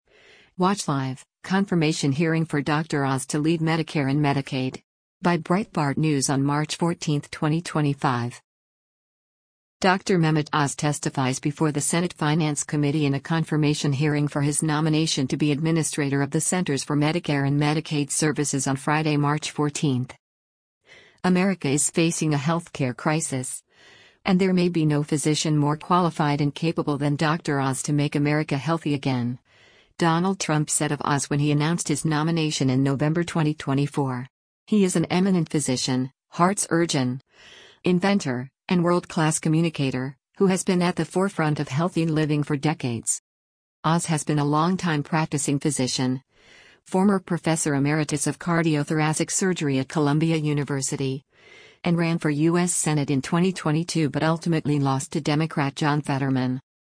Dr. Mehmet Oz testifies before the Senate Finance Committee in a confirmation hearing for his nomination to be Administrator of the Centers for Medicare and Medicaid Services on Friday, March 14.